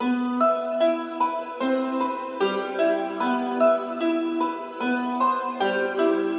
标签： 150 bpm Trap Loops Piano Loops 1.08 MB wav Key : Unknown
声道立体声